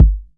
MB Kick (23).wav